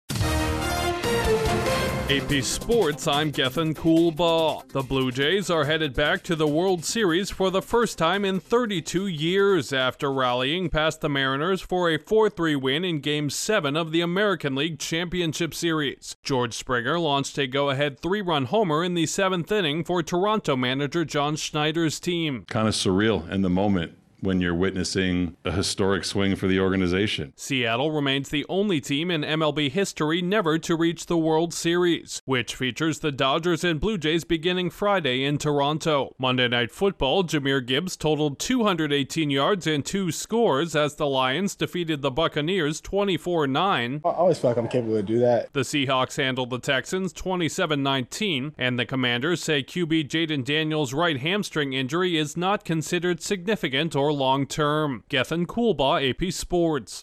A dramatic homer secures Toronto its first World Series berth since 1993 as Seattle again falls short, a running back’s big day helps Detroit get past Tampa Bay and Seattle holds off Houston on Monday Night Football, and Washington’s young star quarterback avoids serious injury. Correspondent